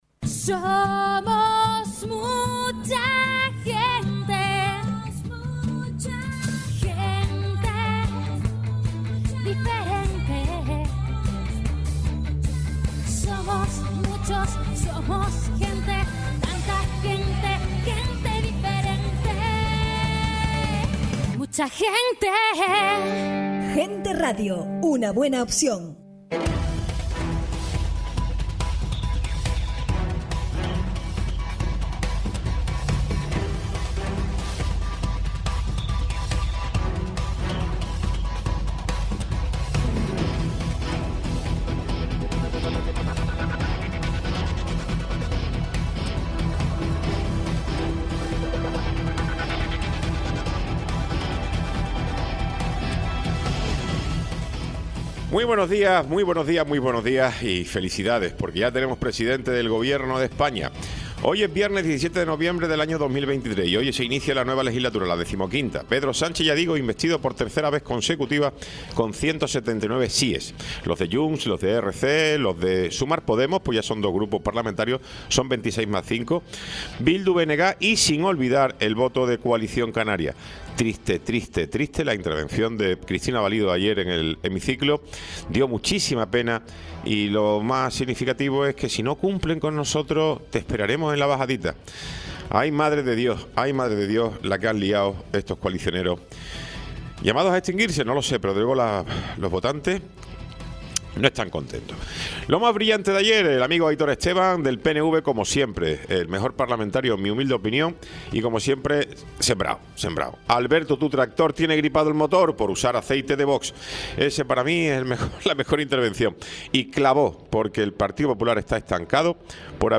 Tiempo de entrevista
Programa sin cortes